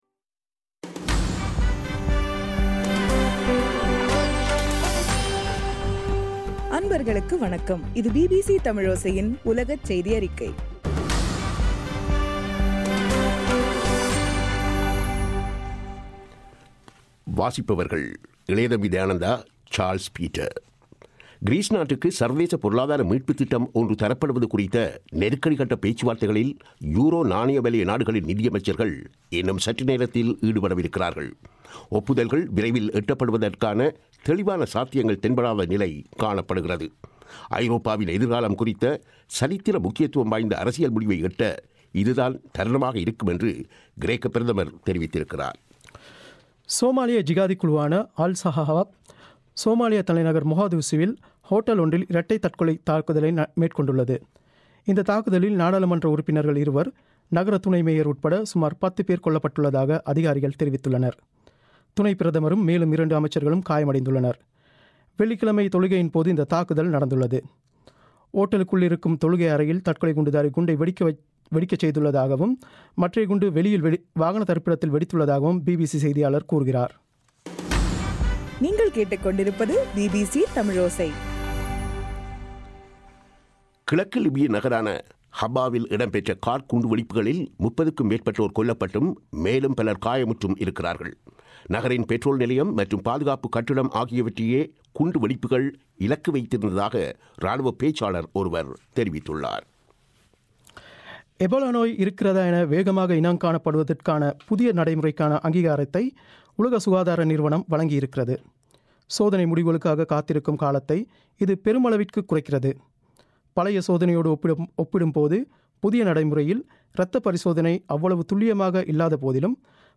தமிழோசையின் உலகச் செய்தியறிக்கை